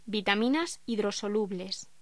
Locución: Vitaminas hidrosolubles
voz